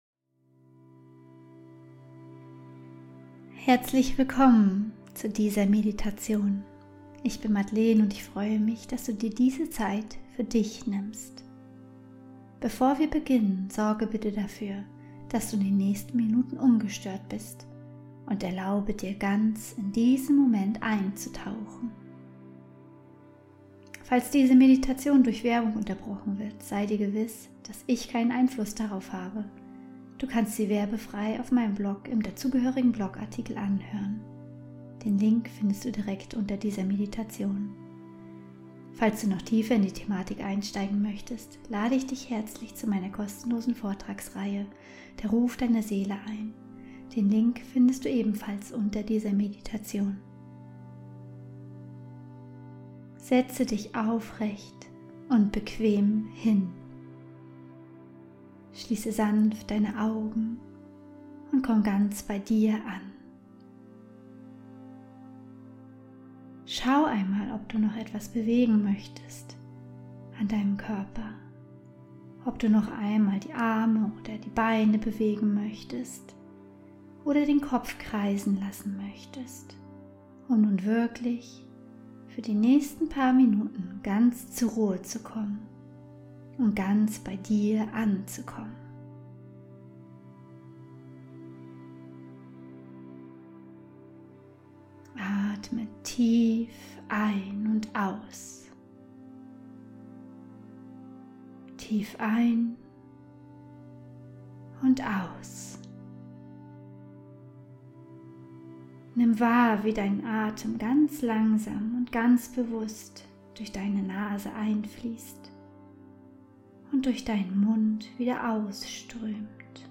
11 Minuten geführte Meditation: Von der Machtlosigkeit zurück in deine Kraft ~ Heimwärts - Meditationen vom Funktionieren zum Leben Podcast